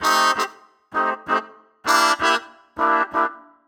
Index of /musicradar/gangster-sting-samples/130bpm Loops
GS_MuteHorn_130-AE.wav